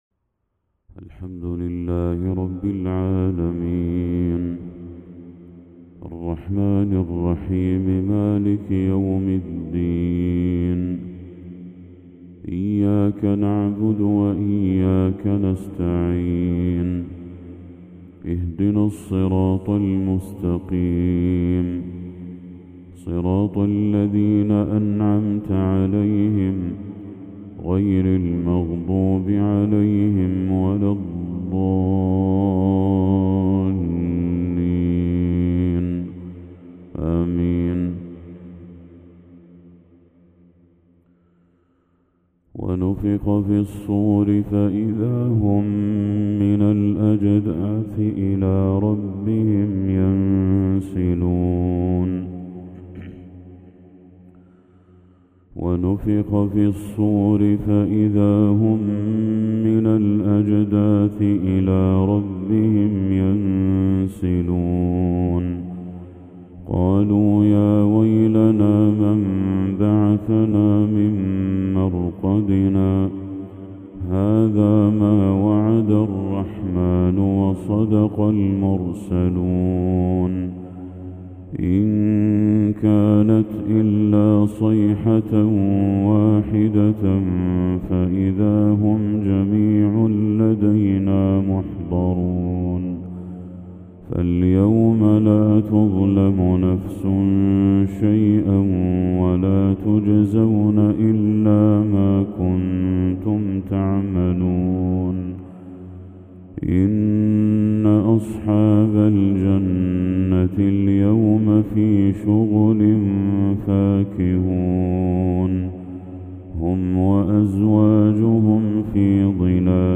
تلاوة أخَّاذة للشيخ بدر التركي خواتيم سورة يس | فجر 20 ذو الحجة 1445هـ > 1445هـ > تلاوات الشيخ بدر التركي > المزيد - تلاوات الحرمين